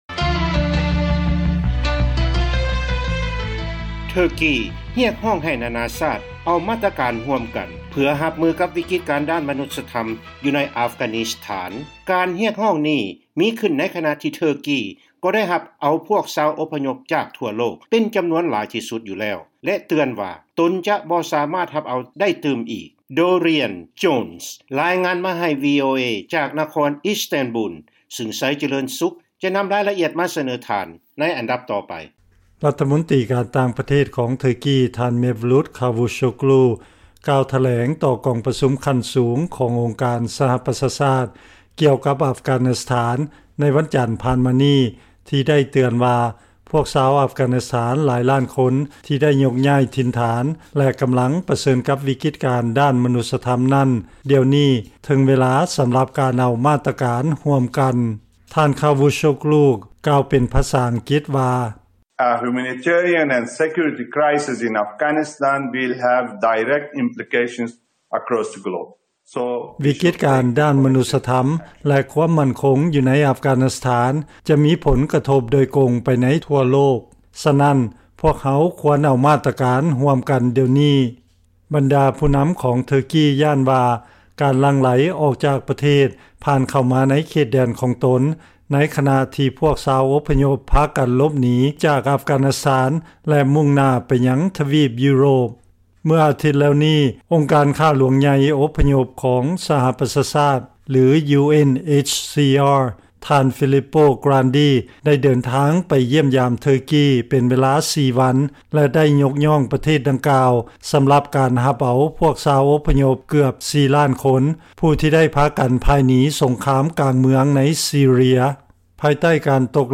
ຟັງລາຍງານ ເທີກີ ຕ້ານທານຕໍ່ການກົດດັນ ໃຫ້ຮັບເອົາພວກອົບພະຍົບ ອັຟການິສຖານ ແລະຮຽກຮ້ອງໃຫ້ໂລກຮັບພາລະ